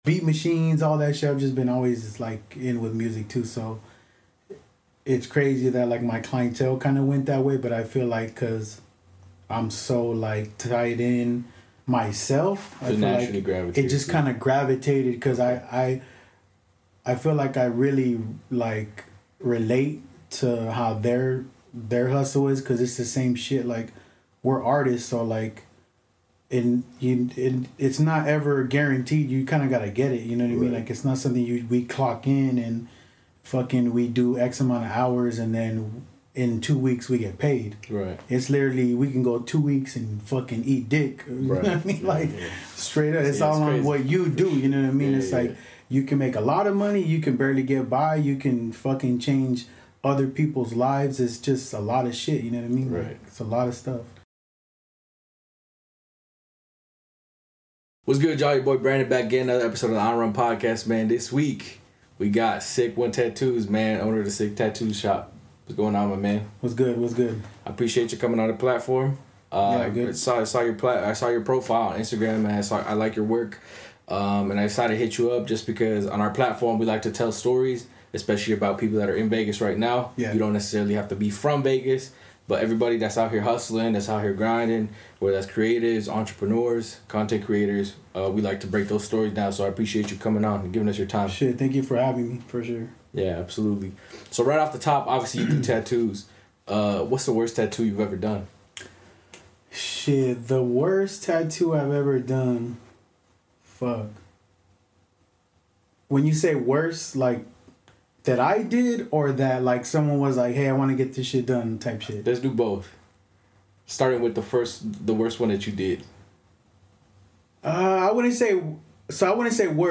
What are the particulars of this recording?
On this episode we take a trip out of the studio